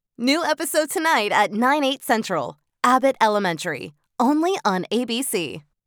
Inglés (Estados Unidos)
Demo comercial
Neumann TLM 193